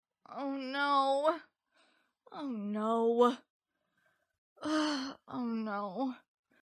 devushka-no-3-var
• Категория: Отрицание - нет
• Качество: Высокое